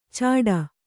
♪ cāḍa